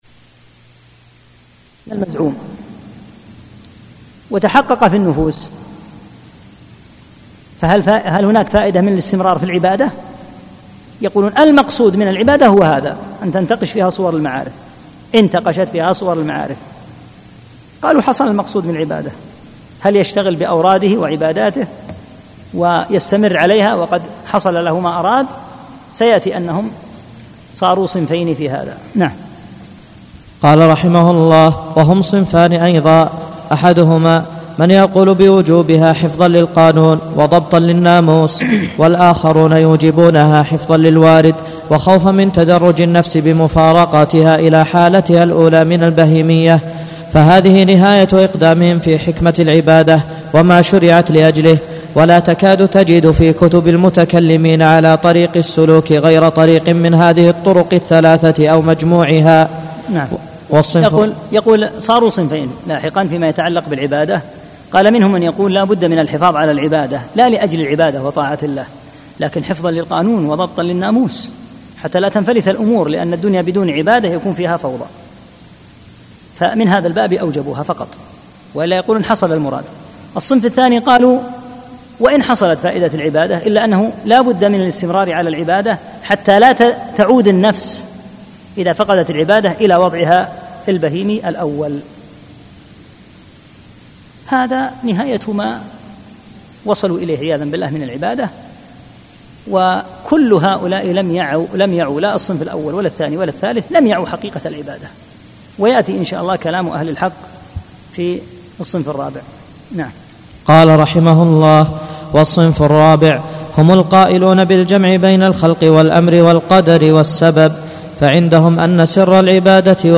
5- الدرس الخامس